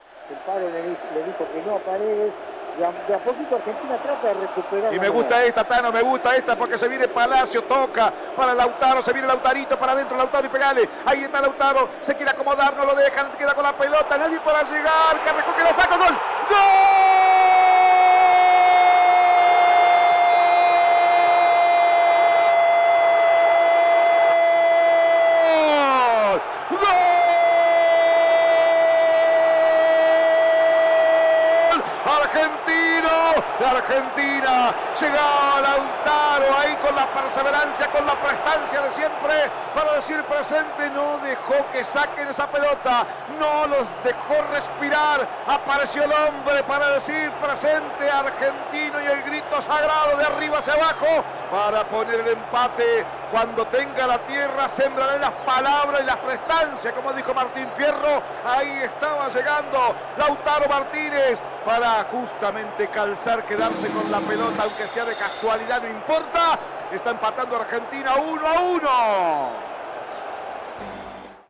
Escuchá los goles de la Selección Argentina en la voz de
GOL-DE-ARGENTINA-01-EDITADO-.mp3